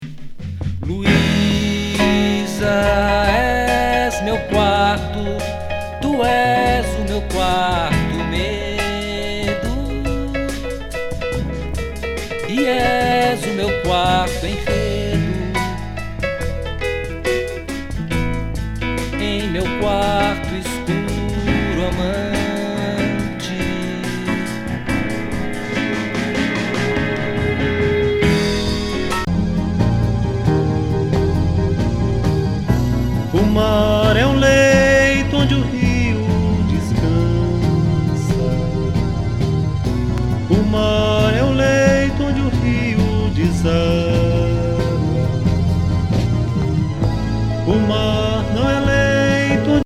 マイナーMPBグループ80年作。ソフト・メロウなボサ・ナンバーからスムース